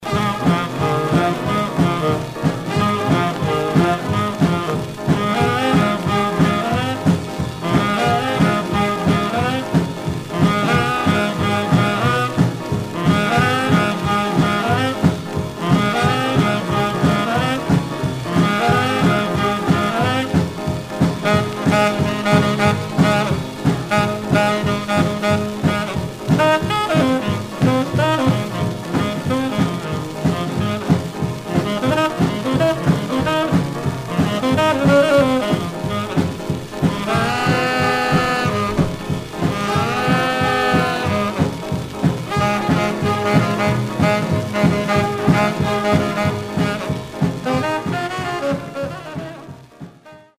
Surface noise/wear Stereo/mono Mono
R&B Instrumental